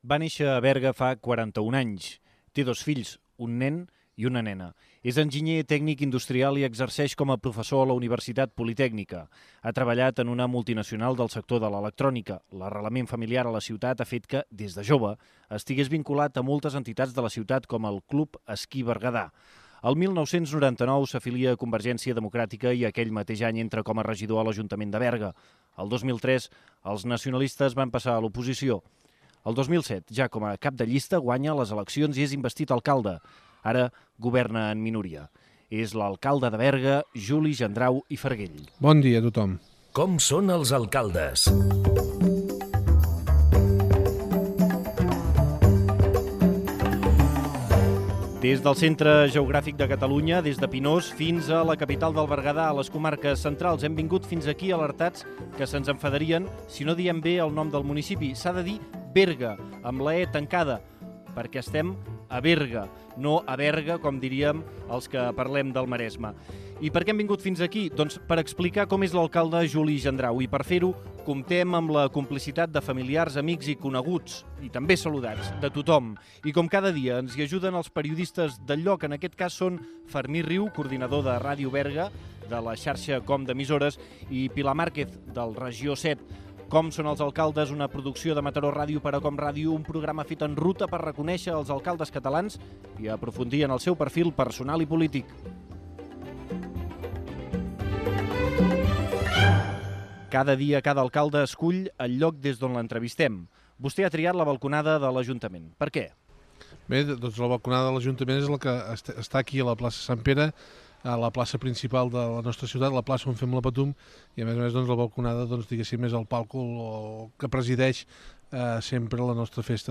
51bbe3d3853fd231d30ccf7a21140fa9ad126cf8.mp3 Títol COM Ràdio Emissora Mataró Ràdio Cadena COM Ràdio Titularitat Pública nacional Nom programa Com són els alcaldes Descripció Entrevista a un alcalde des del seu municipi. Fragment d'una entrevista a l'alcalde de Berga, Juli Gendrau.